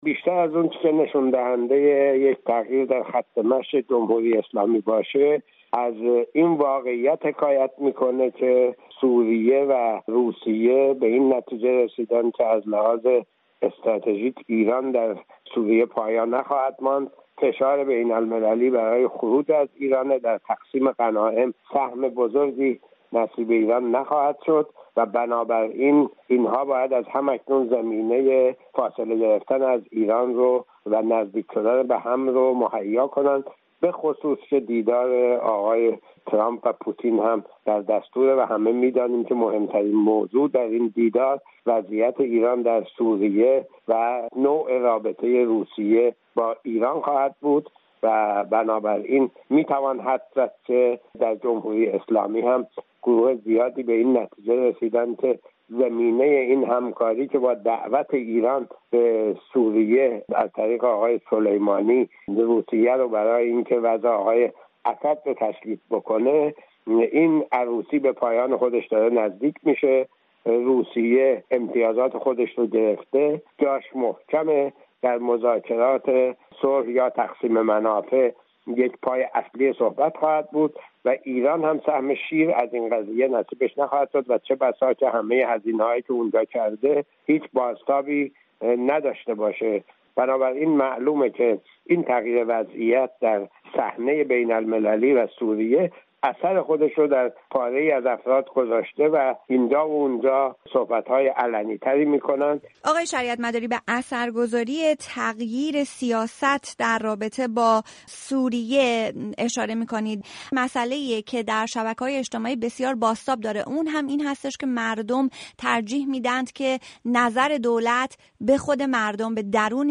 ارزیابی سیاست روسیه و سوریه در قبال ایران در گفت‌وگو
برنامه‌های رادیویی